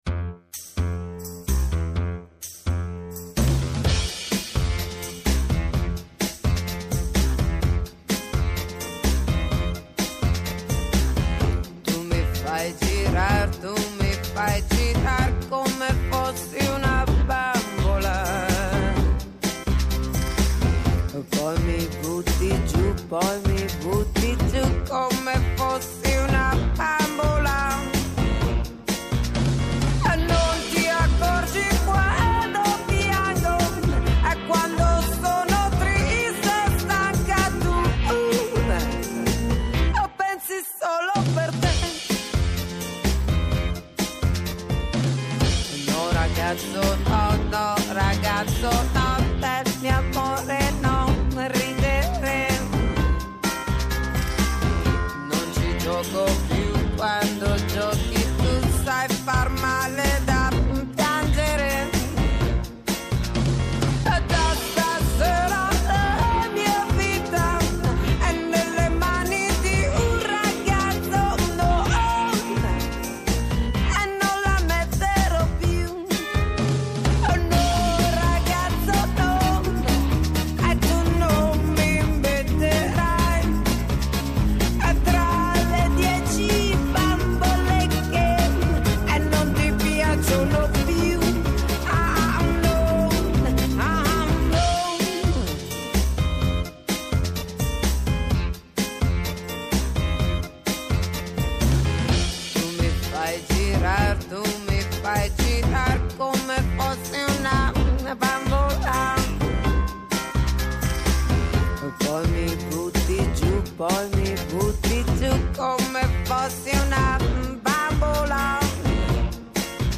Καλεσμένος σήμερα στο studio